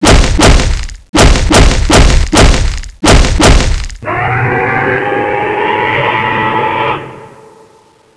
angra_zbs_attack_tentacle2.wav